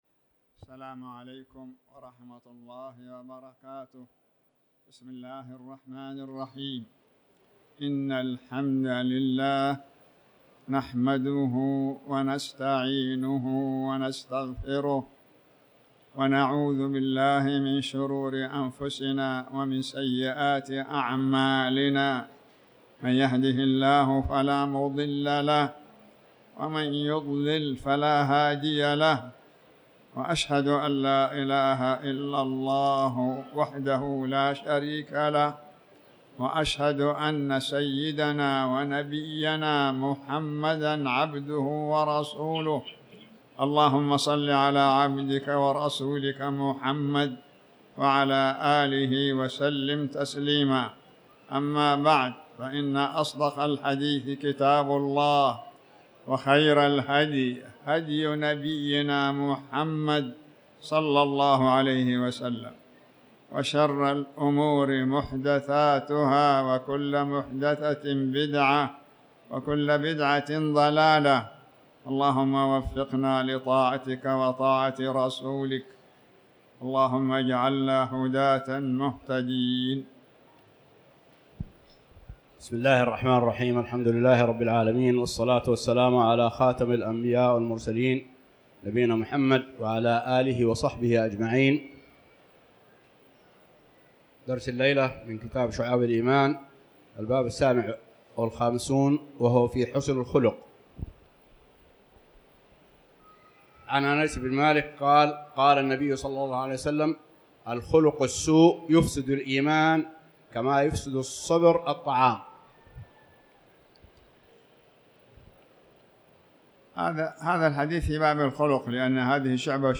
تاريخ النشر ٢٢ شوال ١٤٤٠ هـ المكان: المسجد الحرام الشيخ